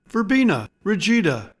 Pronounciation:
Ver-BEE-na RI-gi-da